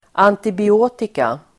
Ladda ner uttalet
antibiotika substantiv, antibiotics Uttal: [antibi'å:tika] Användning: plural; massord Definition: bakteriedödande medel (substances which inhibit the growth of or destroy bacteria and other microorganisms)